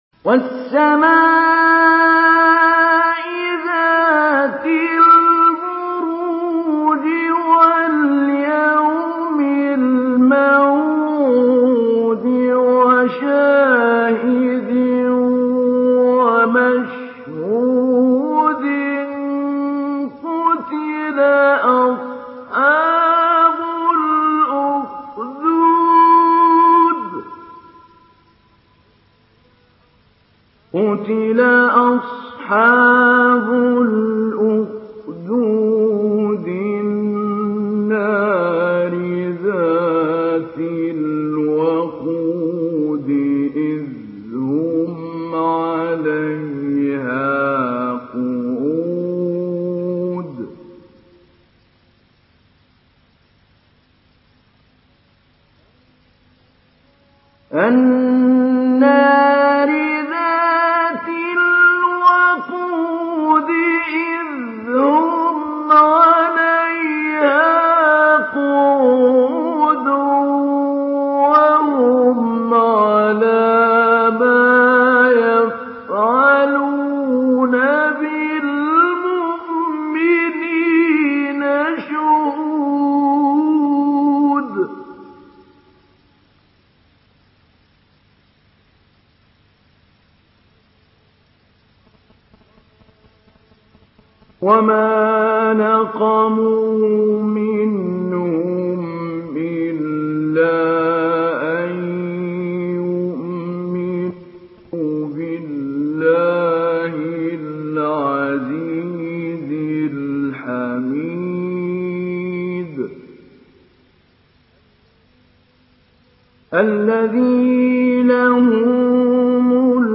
Surah Al-Buruj MP3 in the Voice of Mahmoud Ali Albanna Mujawwad in Hafs Narration
Surah Al-Buruj MP3 by Mahmoud Ali Albanna Mujawwad in Hafs An Asim narration.